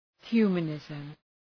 Προφορά
{‘hju:mə,nızm}